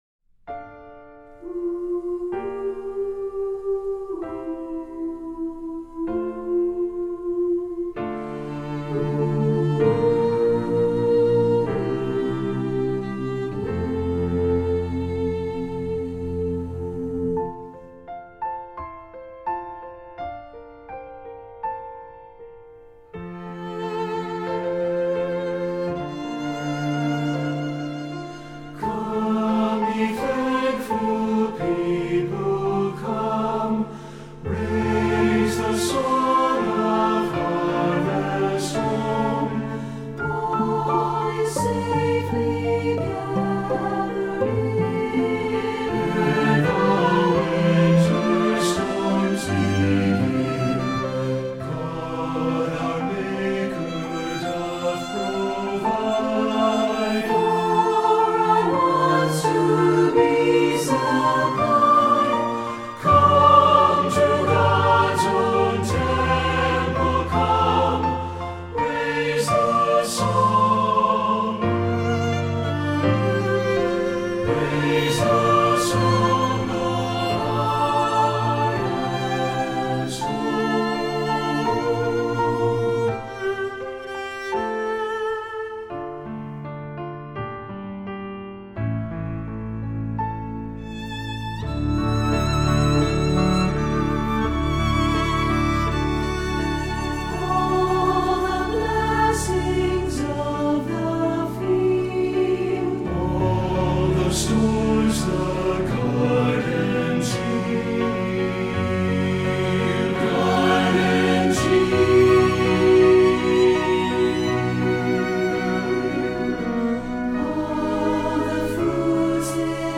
Voicing: SATB, Violin and Cello